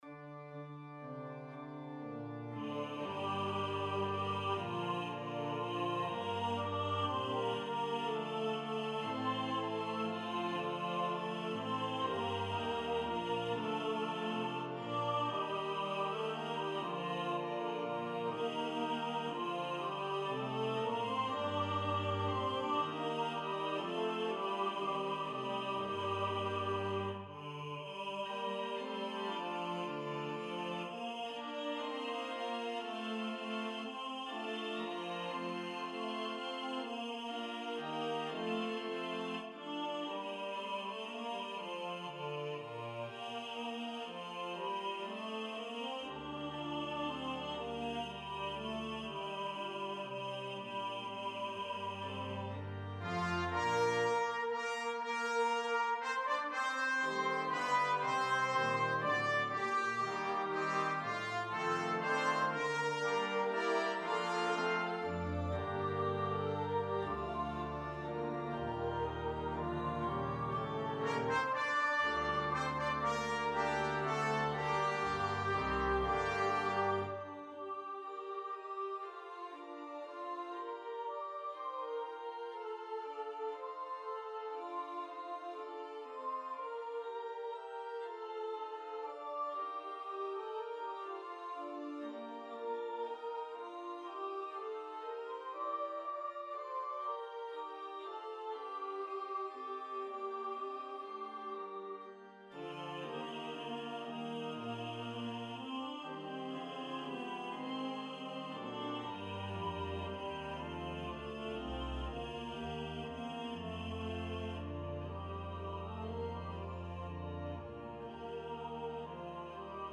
Trumpet 1
Side Drum
Note Performer 4 mp3 Download/Play Audio